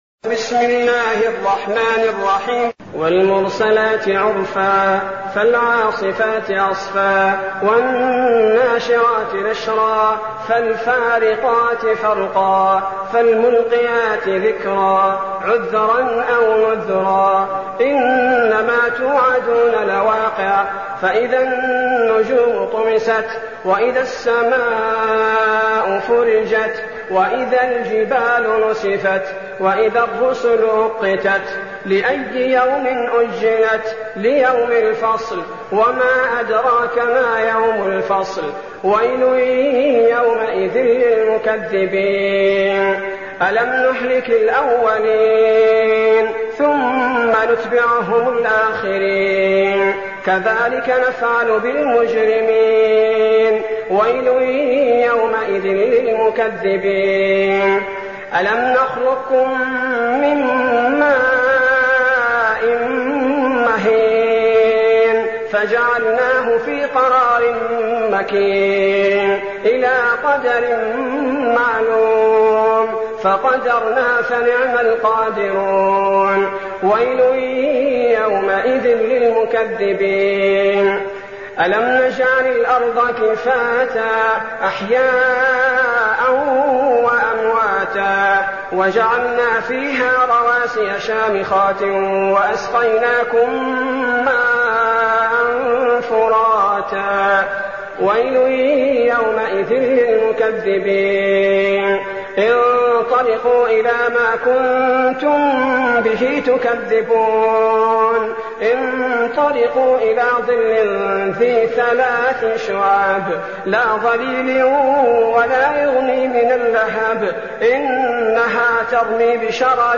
المكان: المسجد النبوي الشيخ: فضيلة الشيخ عبدالباري الثبيتي فضيلة الشيخ عبدالباري الثبيتي المرسلات